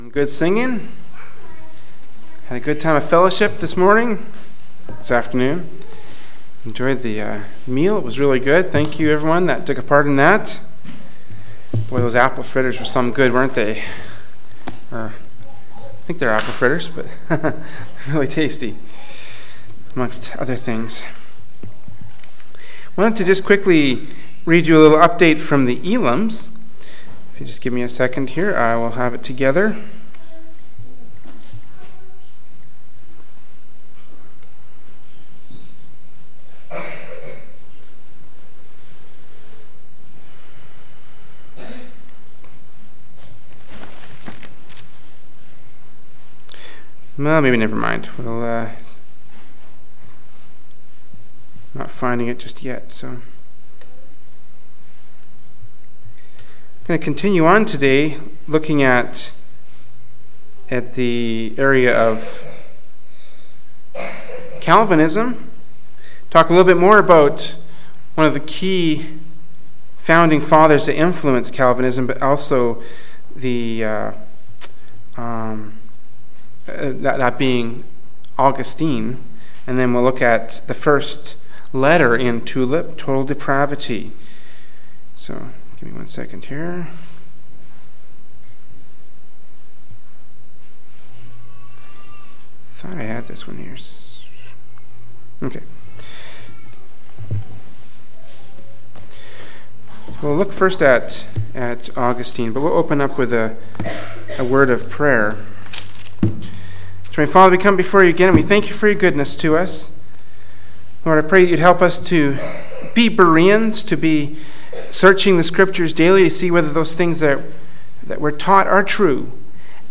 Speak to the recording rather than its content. Sunday Afternoon Service